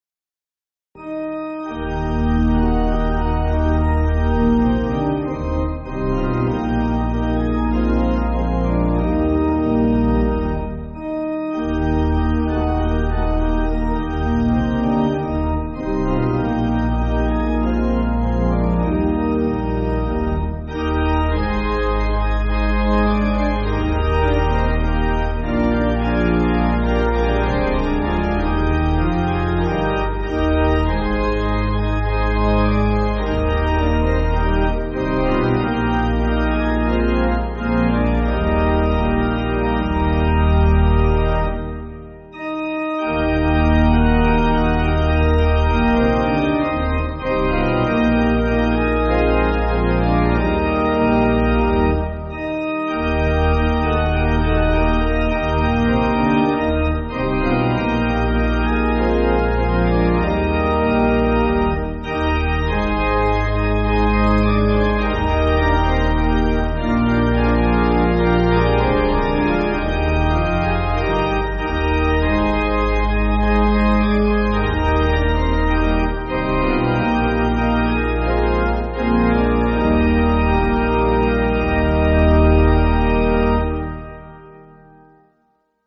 (CM)   2/Eb